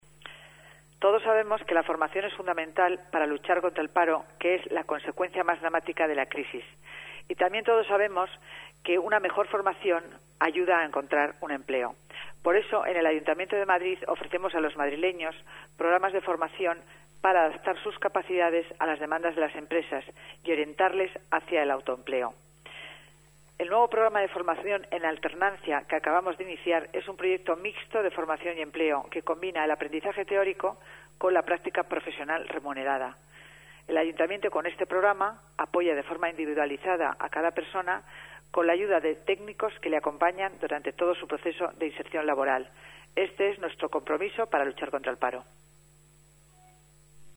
Nueva ventana:Declaraciones de la delegada de Hacienda, Concepción Dancausa